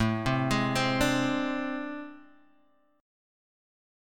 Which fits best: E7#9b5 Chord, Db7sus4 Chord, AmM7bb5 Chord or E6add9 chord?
AmM7bb5 Chord